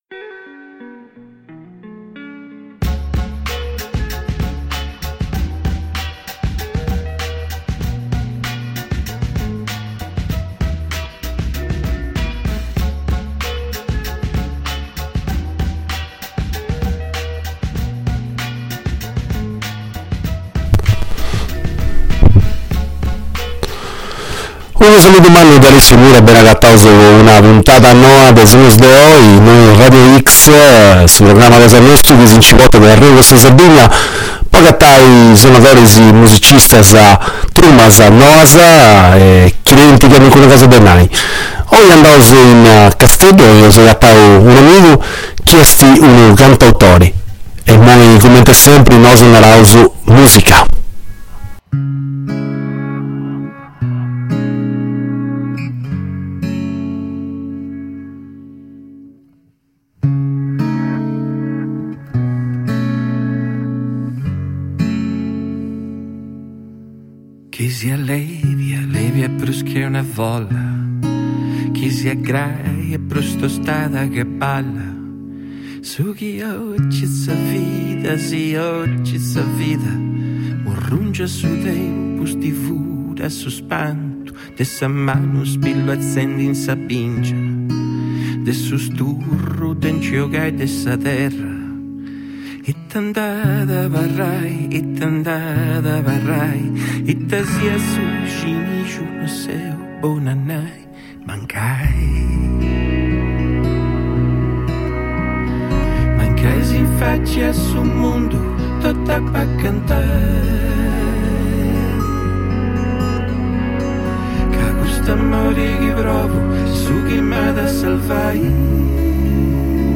Le collaborazioni, il teatro, la musica, la vita, i dischi pubblicati e quelli che ancora devono uscire. I tempi che stiamo vivendo e come questi tempi entrano nella nostra vita e nella musica che produciamo. Di tutto questo abbiamo parlato con un artista che scrive a canta nella nostra lingua